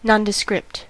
nondescript: Wikimedia Commons US English Pronunciations
En-us-nondescript.WAV